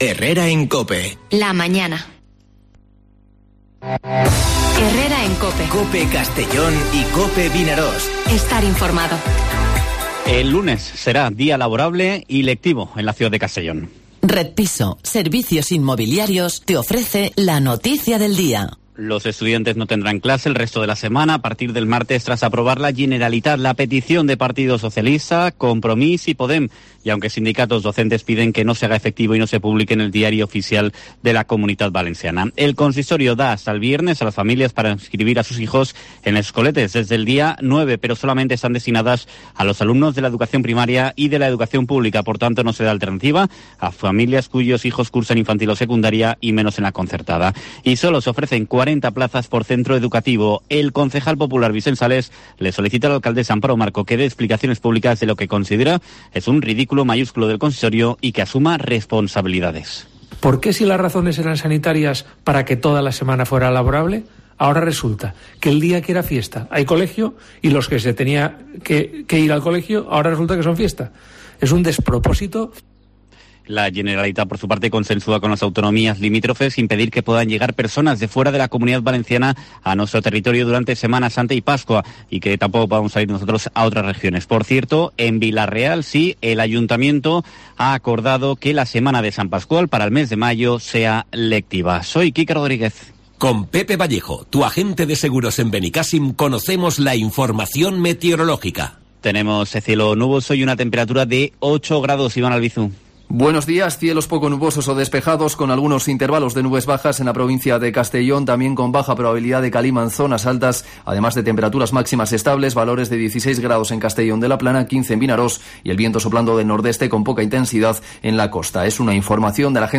Informativo Herrera en COPE en la provincia de Castellón (04/03/2021)